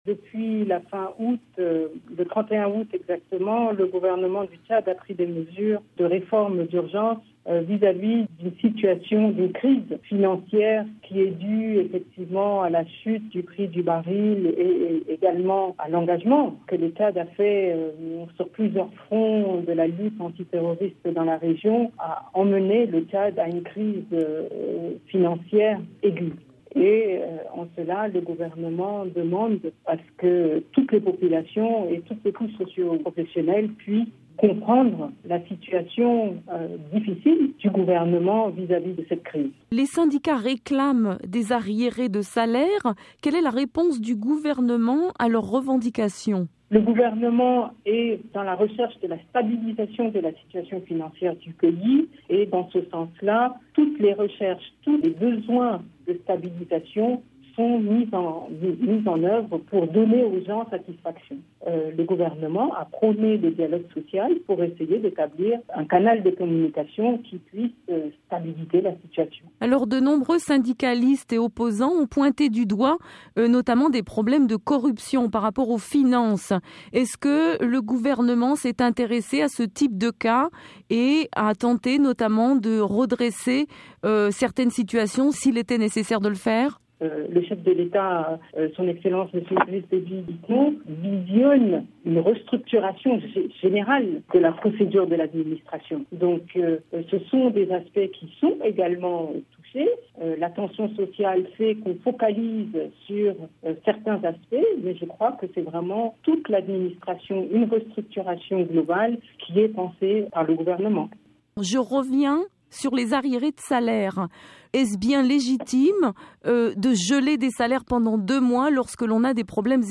Crise financière et sociale au Tchad- Madeleine Alingué, porte-parole du gouvernement